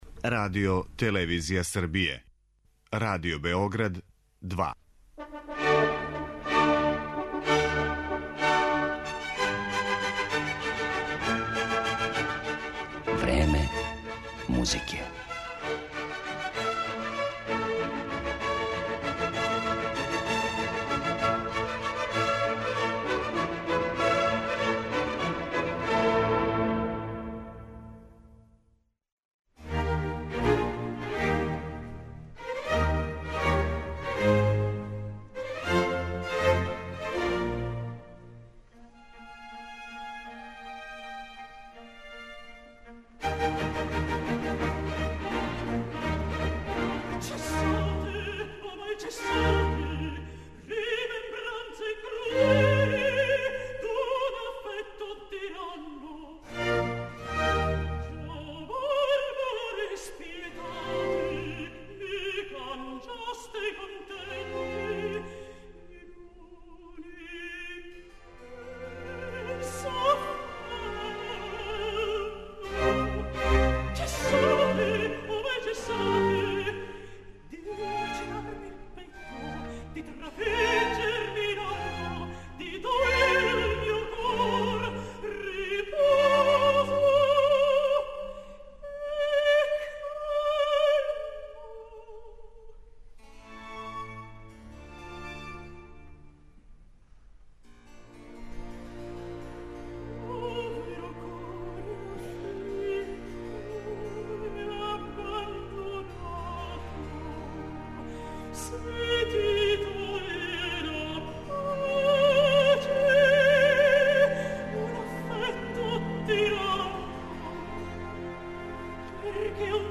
Данашња емисија је посвећена је изузетној италијанској вокалној солисткињи Сари Мингардо.
Њен глас стручњаци једногласно дефинишу као прави контраалт и без изузетка га описују као редак феномен у вокланој уметности.